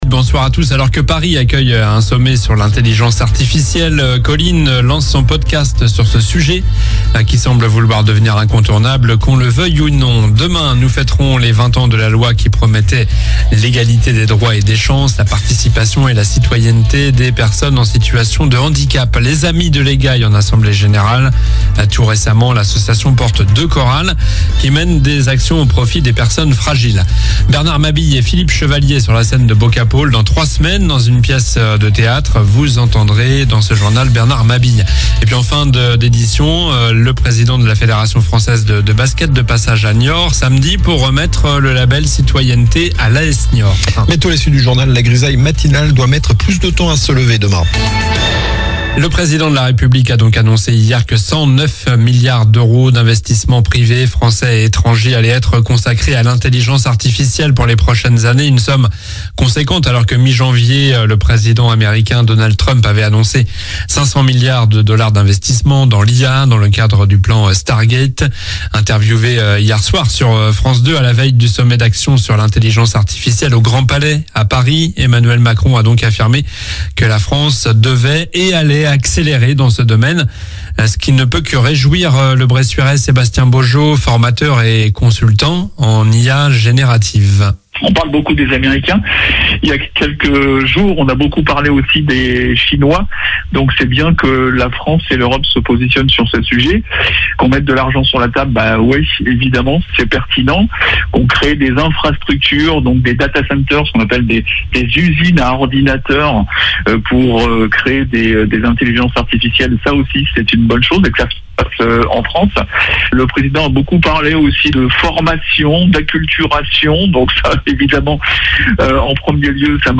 Journal du lundi 10 février (soir)